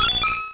Cri d'Hypotrempe dans Pokémon Rouge et Bleu.